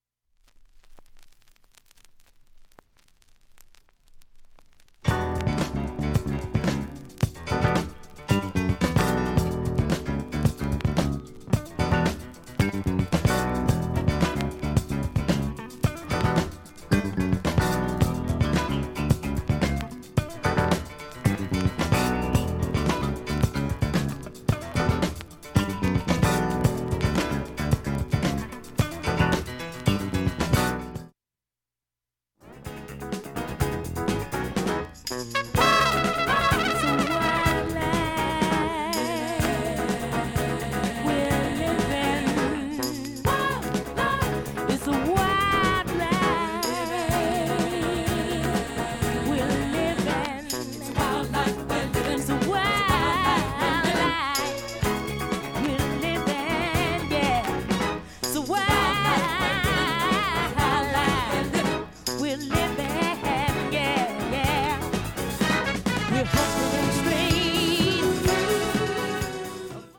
薄いスレ程度問題無し音質良好全曲試聴済み
かすかな無音部チリ聴き取りにくい程度
７０秒の間に周回プツ出ますがかすかです。
良質レアグルーヴトラックが満載